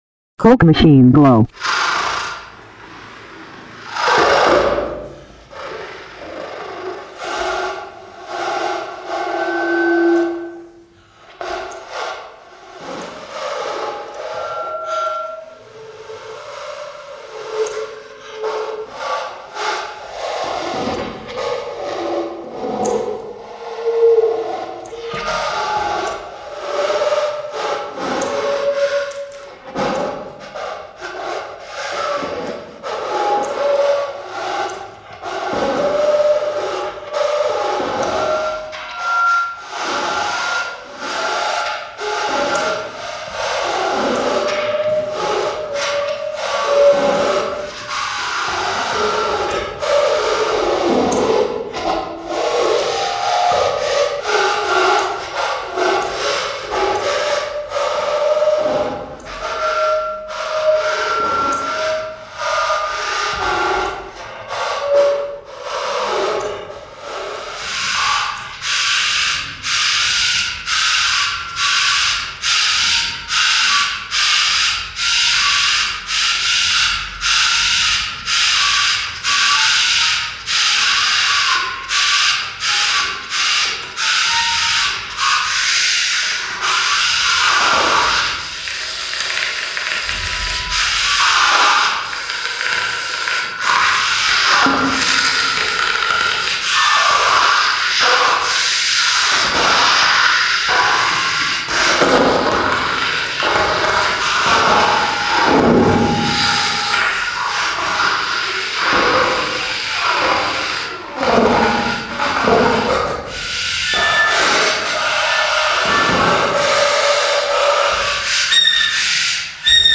Enjoy your jazz consolation prize to the sounds of